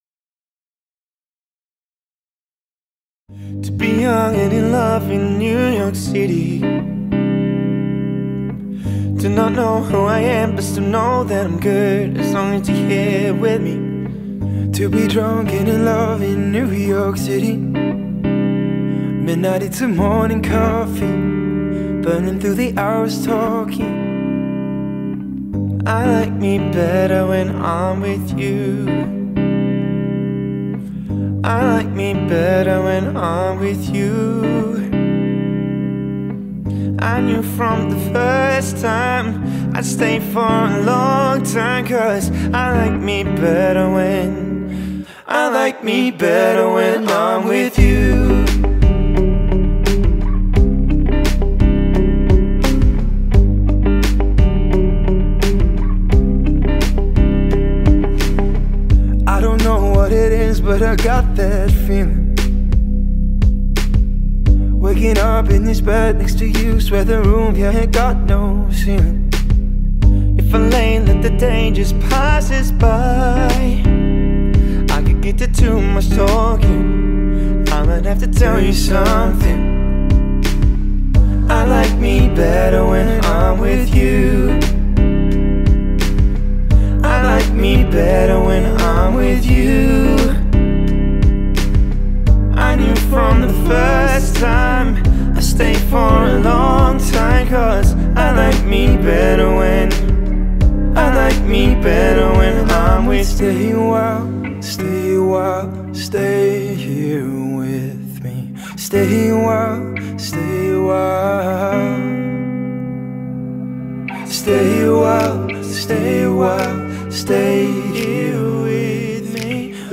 Dual Vocals | Dual Guitars | Looping | DJ | MC
acoustic looping duo